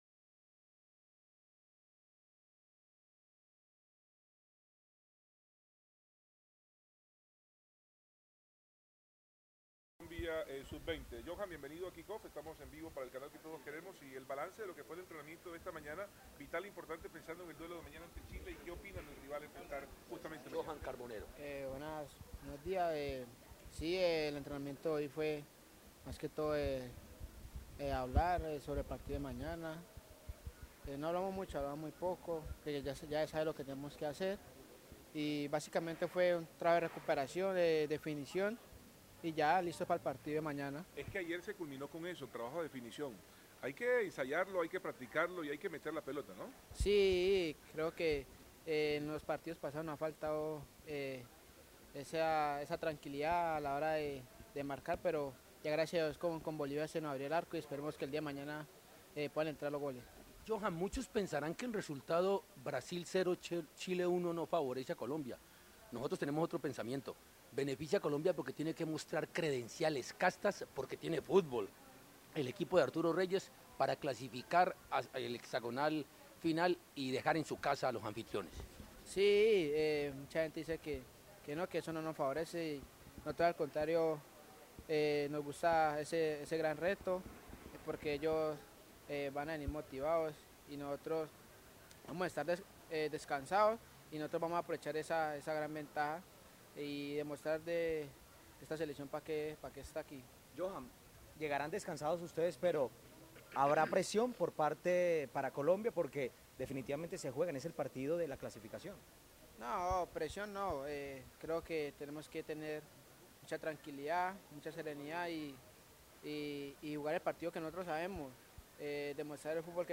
Luego del entrenamiento, cuatro jugadores atendieron a la prensa en su concentración: